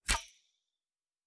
Weapon UI 09.wav